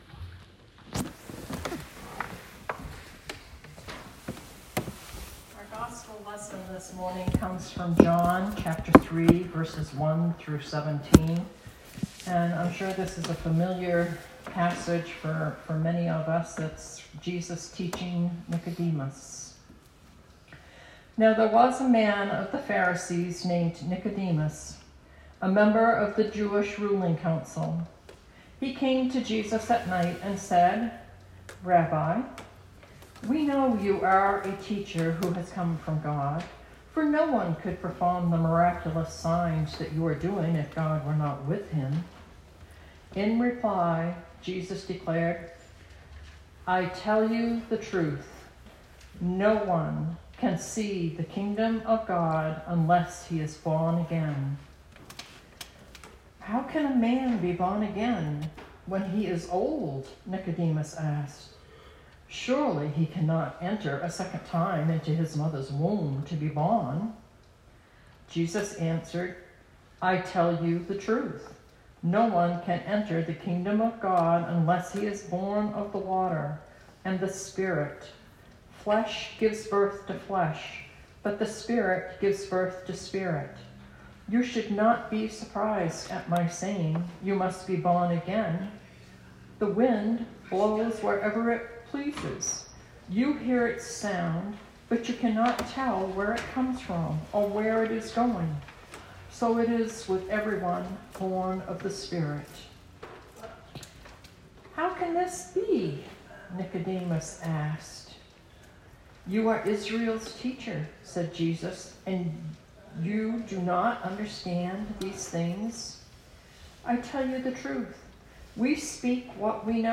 Sermon 2020-03-08